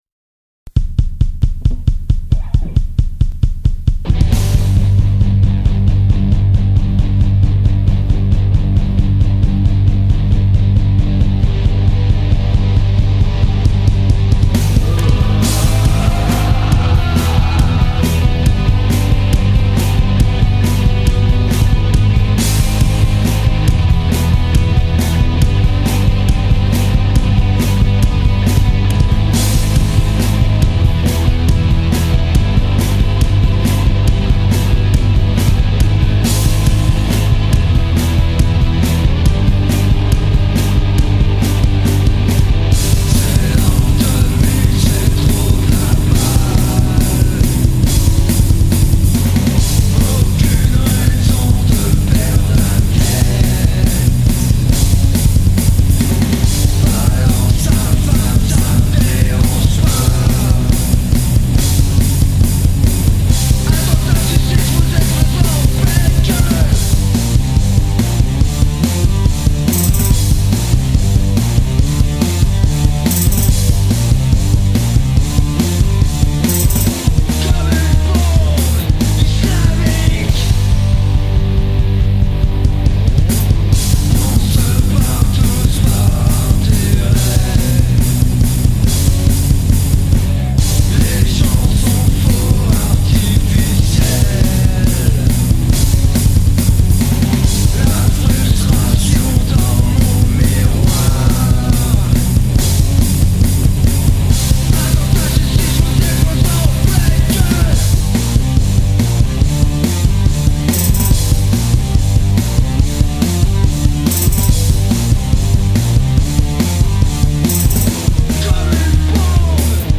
guitare voix boite à ryhtme